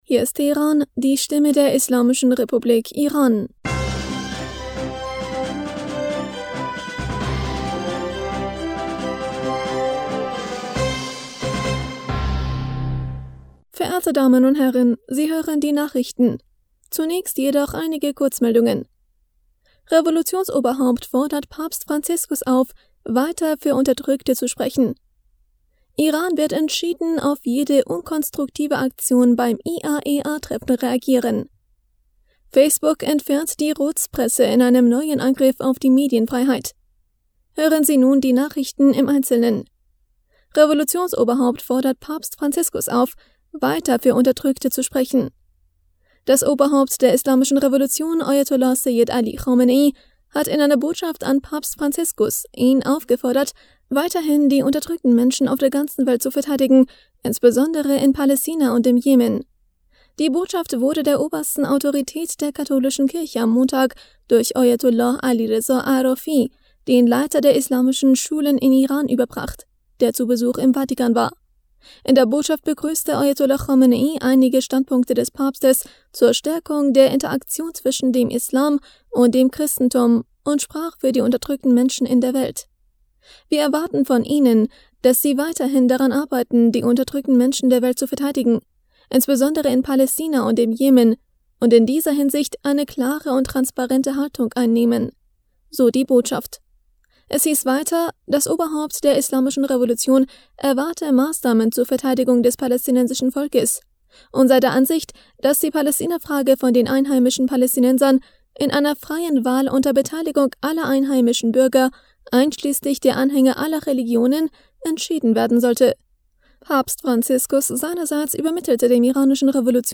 Nachrichten vom 2. Juni 2022